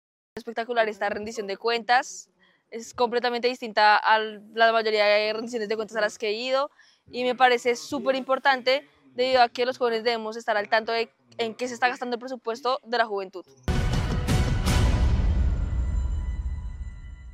Cerca de 100 jóvenes de todo Caldas llegaron hasta Cameguadua, en Chinchiná, para asistir a la Rendición de Cuentas de Juventud 2025, liderada por la Secretaría de Integración y Desarrollo Social del departamento.